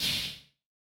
MOO Snare 1.wav